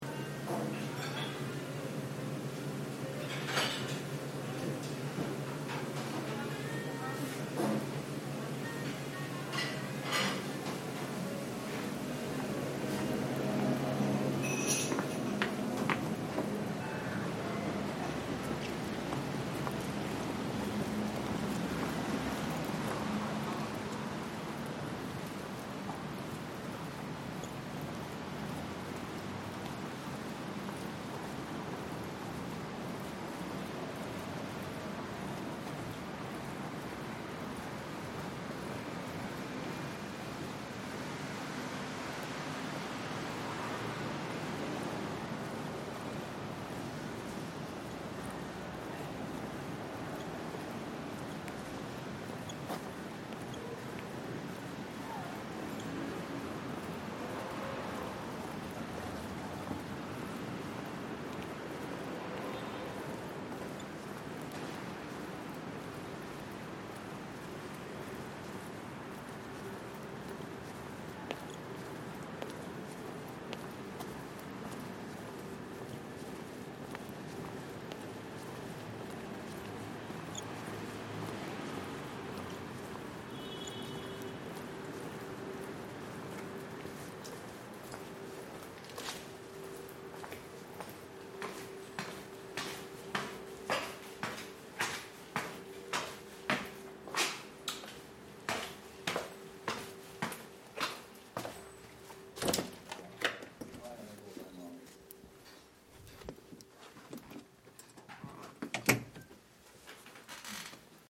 A rainy evening in Tokyo
On a rainy evening in Tokyo, walking from New Yorker's Cafe to Ftarri, an experimental/free improv venue/CD shop.